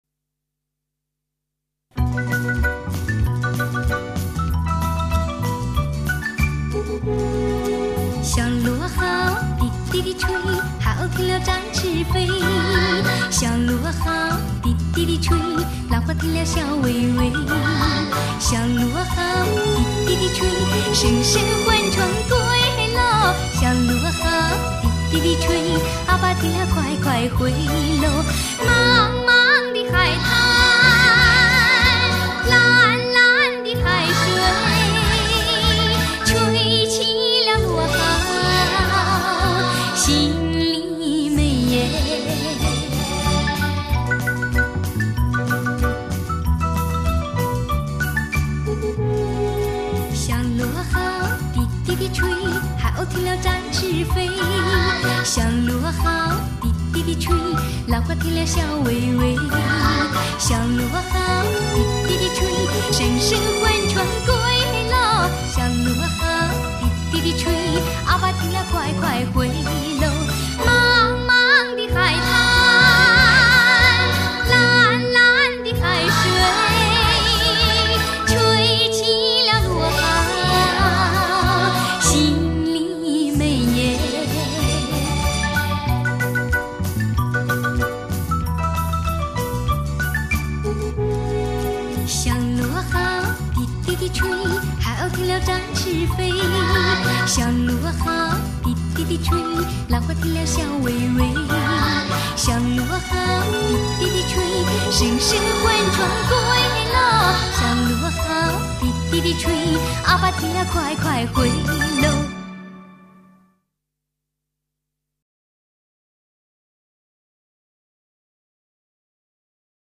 其声音清新甜美。